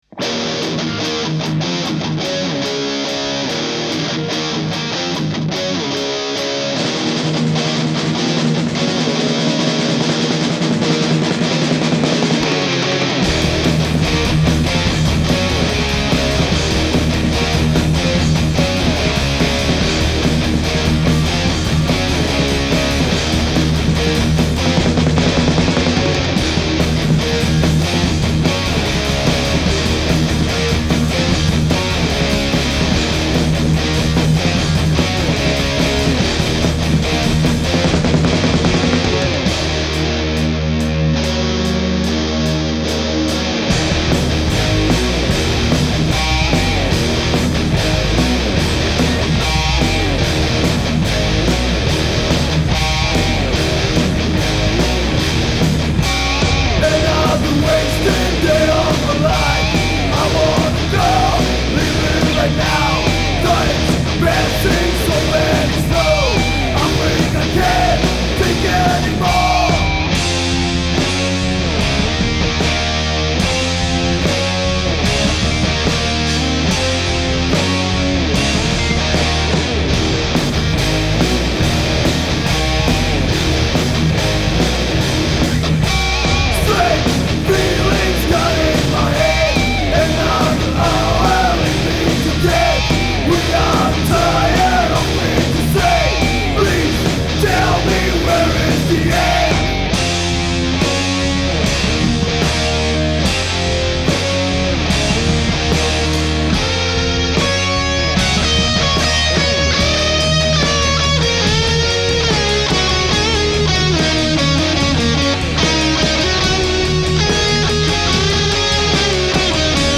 Gramy mocną muzykę gitarową.
Gatunek: Metal
wokal, gitara
perkusja